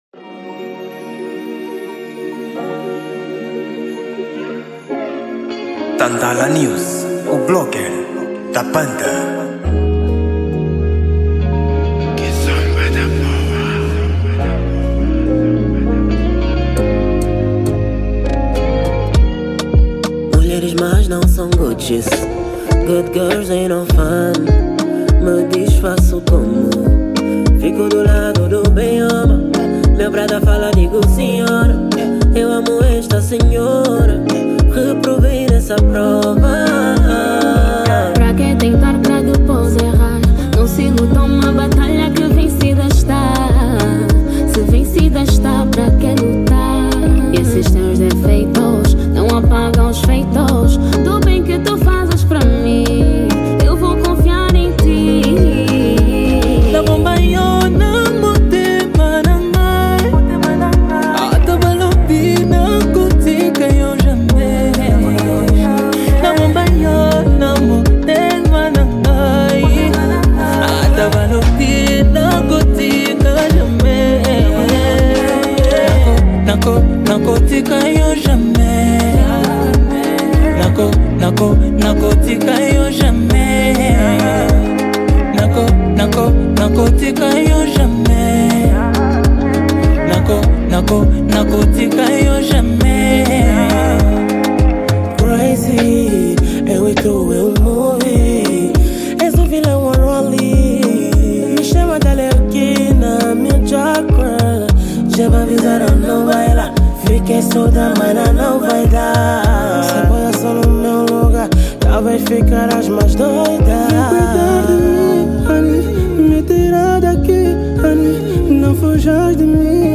Gênero: Kizomba